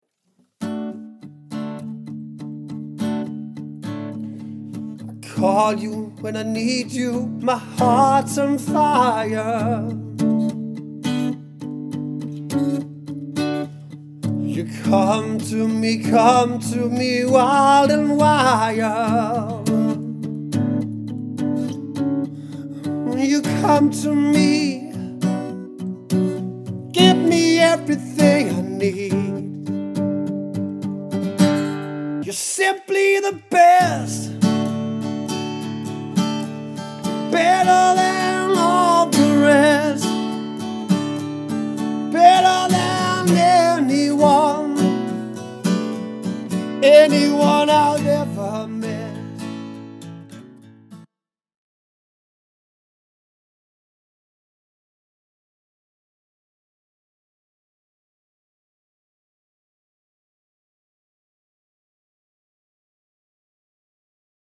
Jeg spiller guitar og synger, og det er det bedste jeg ved.